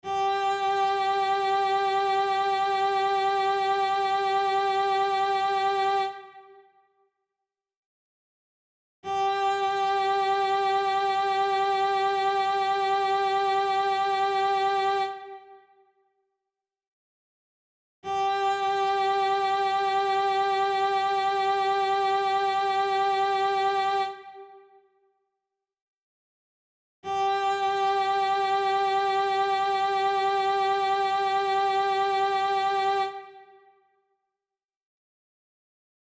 Listen to the note ... this is a little lower than the 2nd note, sing it loudly!